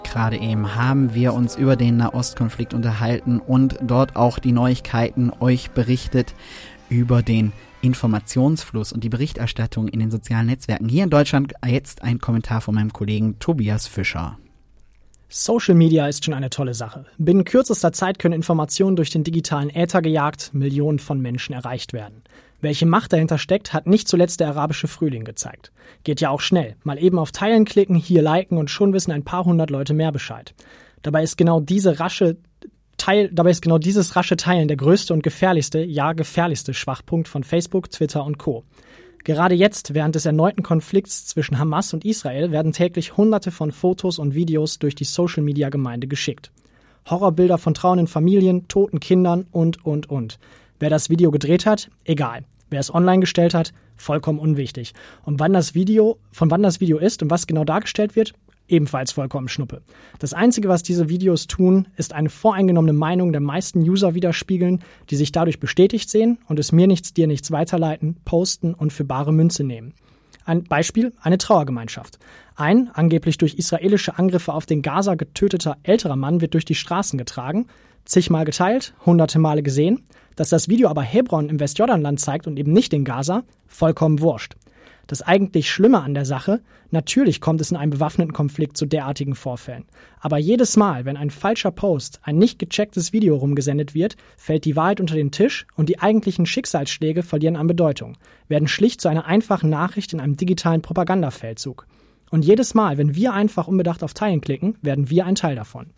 Kommentar: Der Nahost-Konflikt in den Sozialen Netzwerken